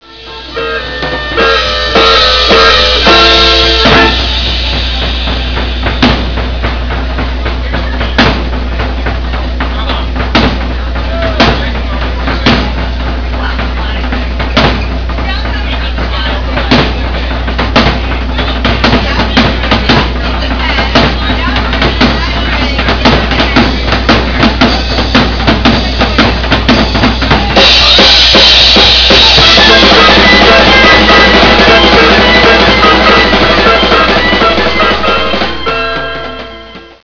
.wav file 409k), from an October 1981 gig at The Mousetrap.